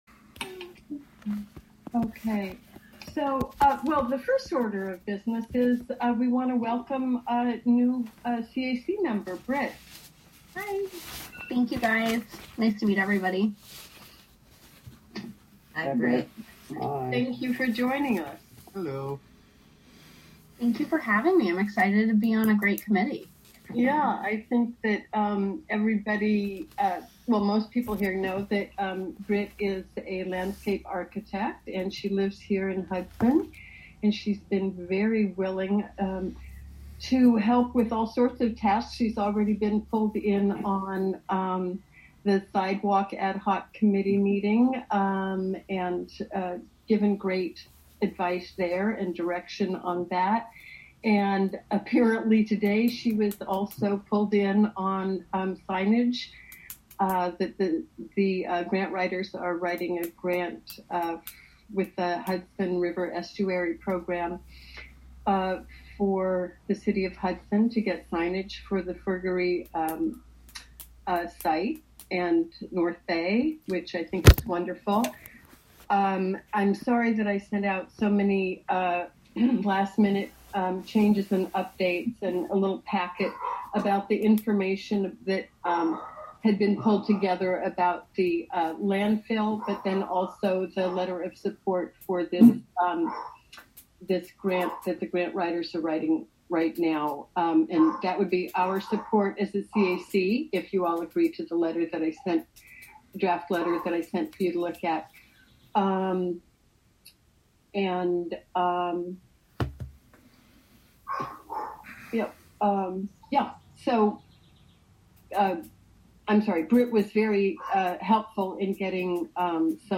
Live from the City of Hudson: Hudson Conservation Advisory Council (Audio)